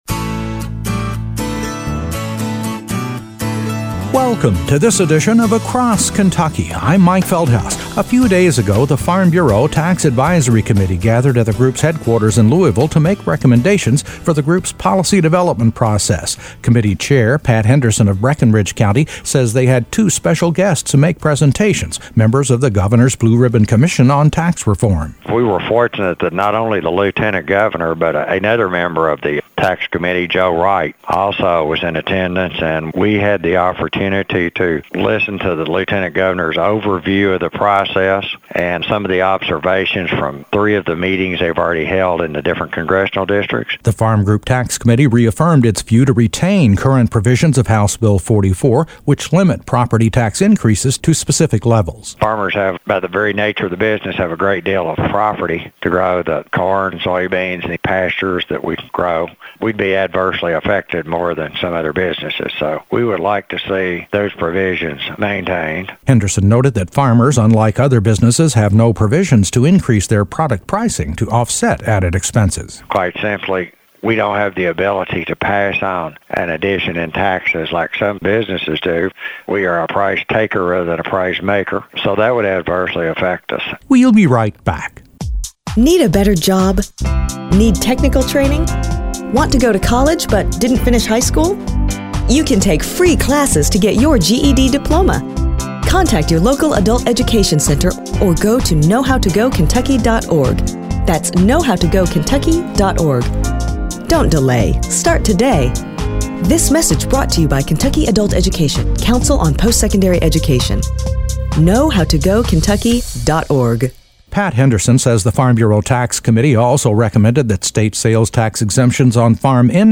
A report on farmer concerns as the Governor’s Blue Ribbon Commission on Tax Reform continues its meetings across the state.